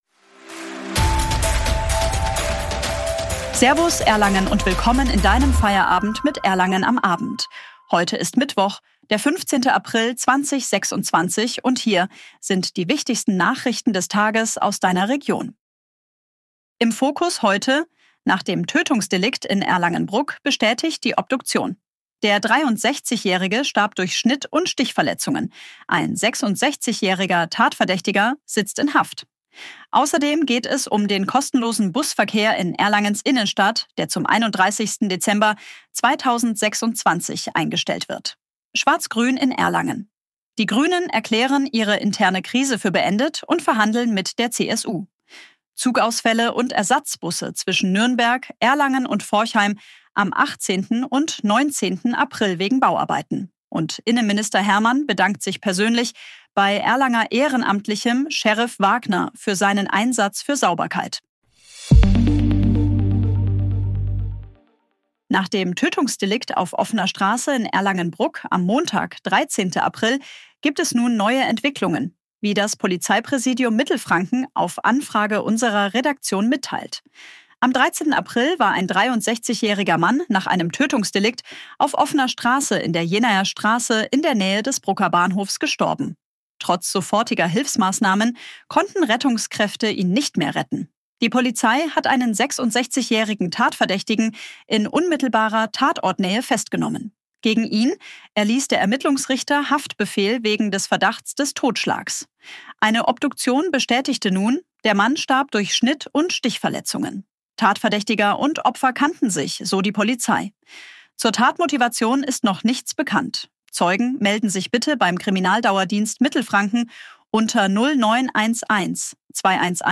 auf den Punkt – dein täglicher Nachrichtenüberblick zum Feierabend.